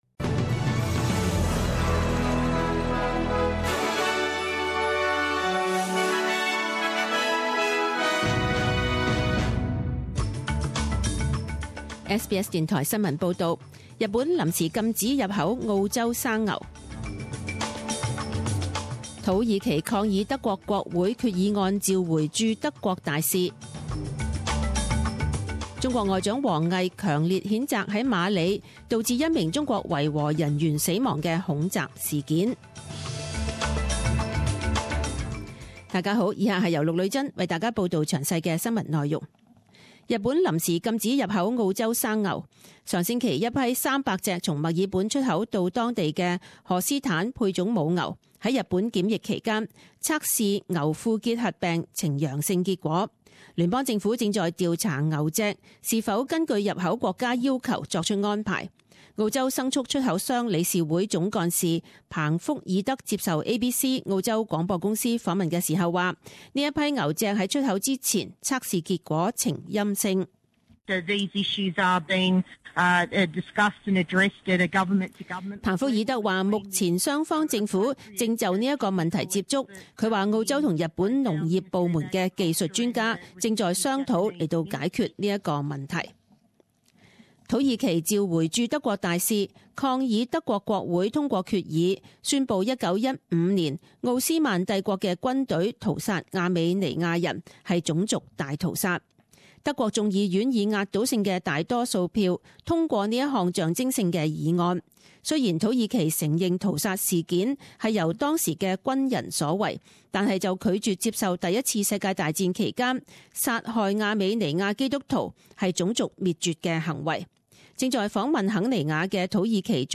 十点钟新闻报导 （六月三日）